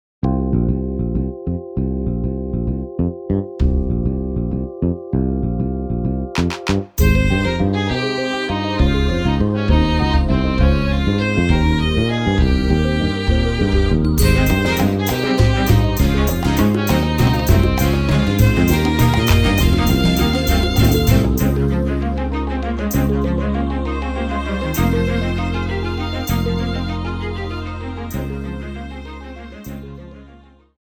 スピーディーで爽快な変拍子祭を展開！